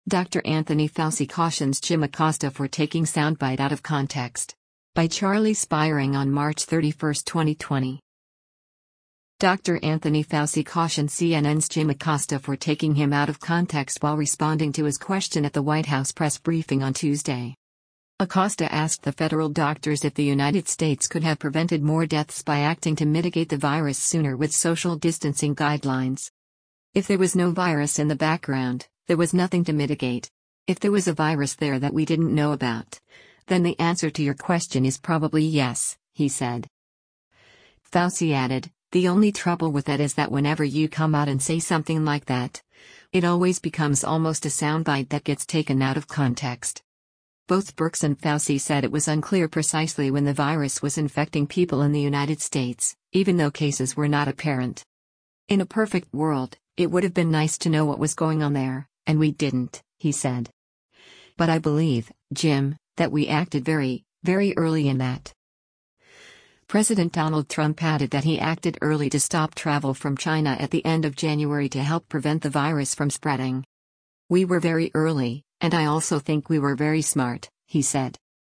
Dr. Anthony Fauci cautioned CNN’s Jim Acosta for taking him out of context while responding to his question at the White House press briefing on Tuesday.